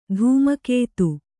♪ dhūma kētu